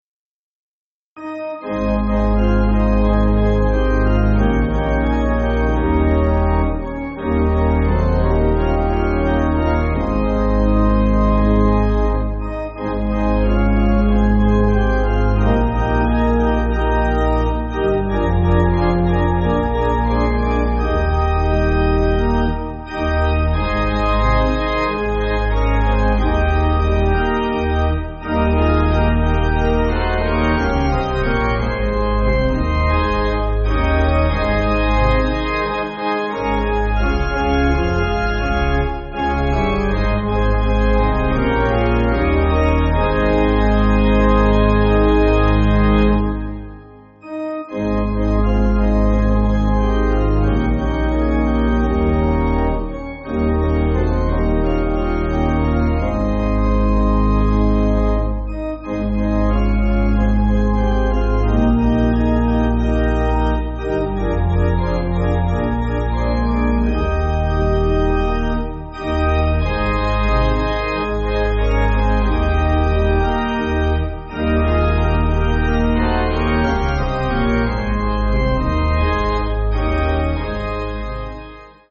Organ
(CM)   3/Ab